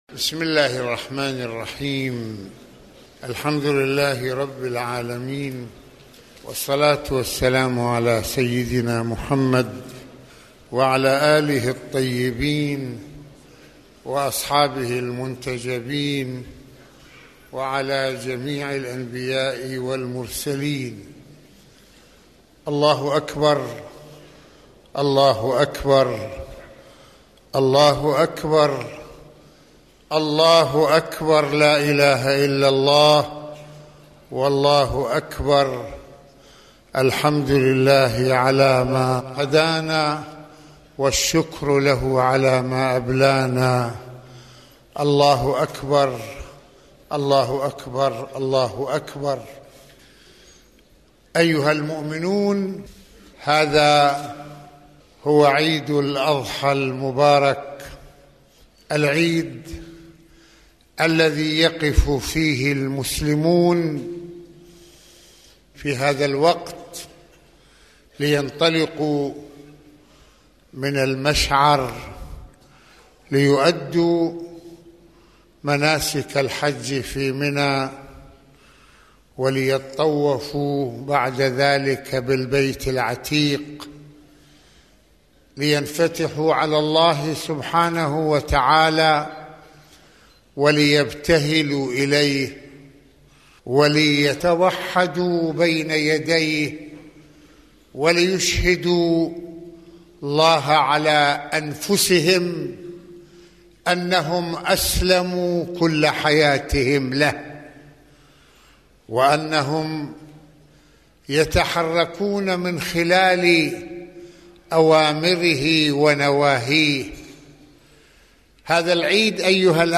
خطبة عيد الأضحى
مسجد الإمامين الحسنين (ع)